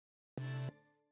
map_open_oneshot_001.wav